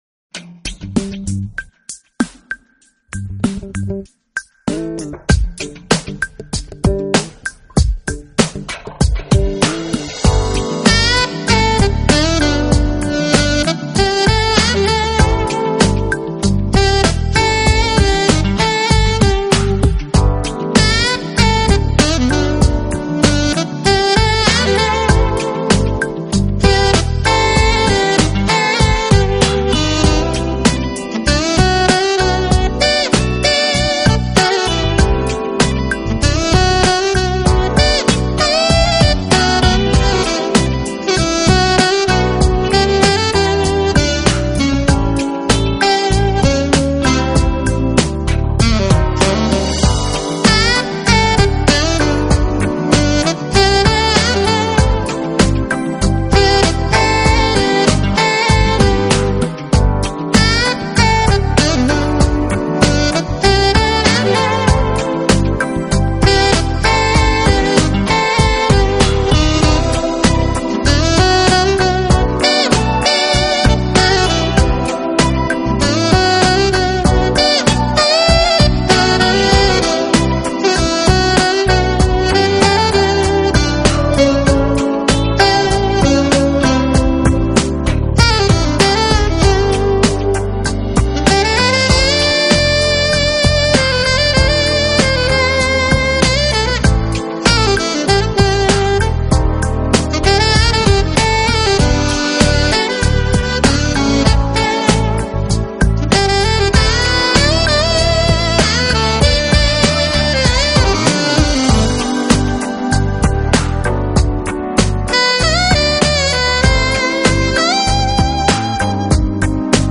【爵士萨克斯】
风格：Smooth Jazz